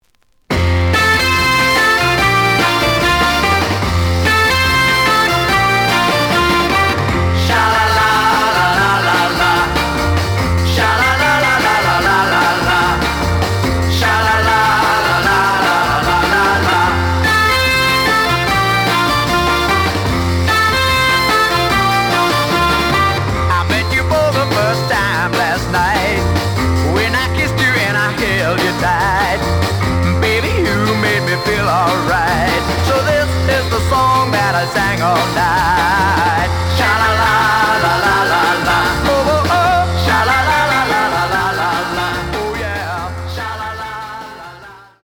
The audio sample is recorded from the actual item.
●Format: 7 inch
●Genre: Rock / Pop
A side plays good.